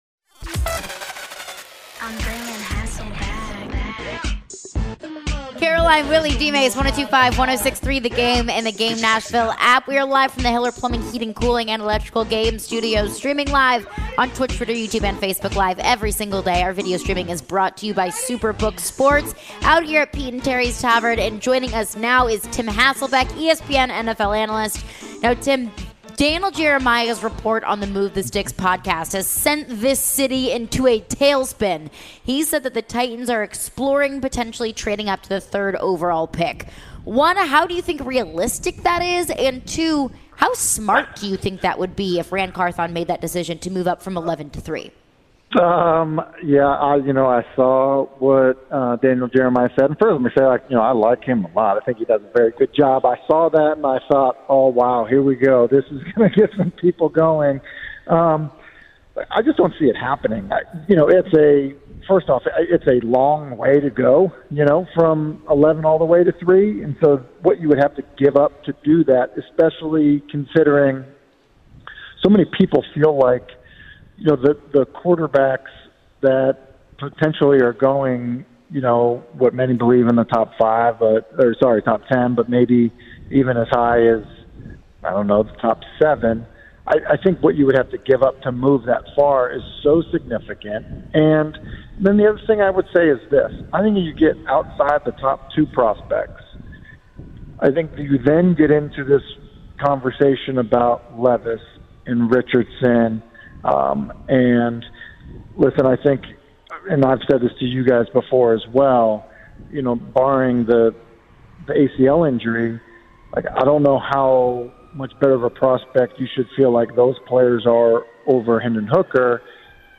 ESPN NFL Analyst Tim Hasselbeck joined for his weekly visit to breakdown the latest in the NFL & Tennessee Titans.